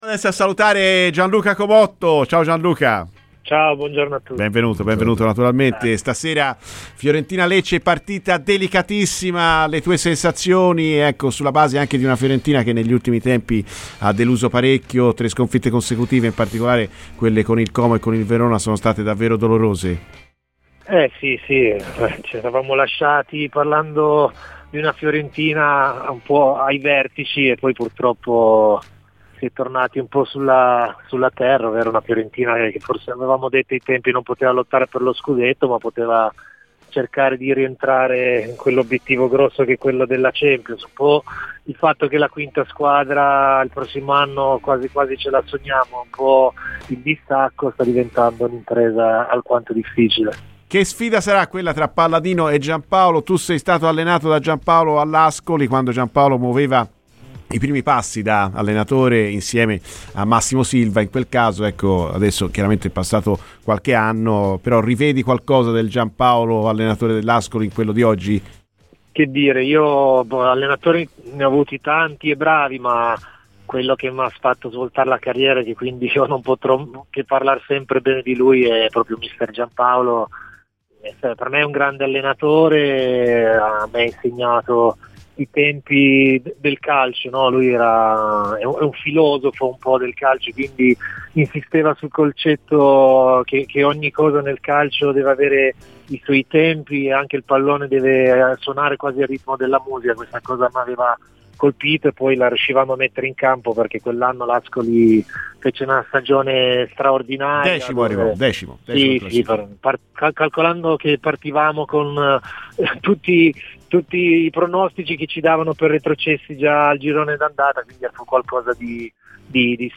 è intervenuto a Radio FirenzeViola durante il programma "Viola Amore Mio"